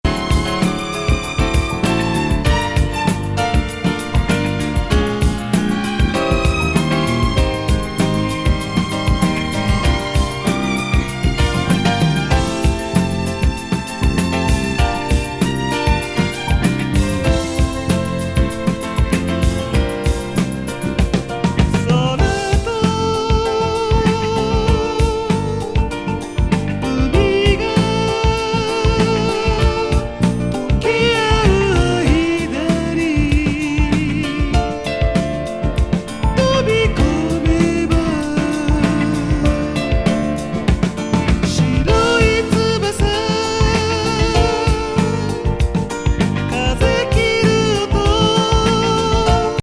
ストリングス・フィリー＋ファルセットなディスコティーク・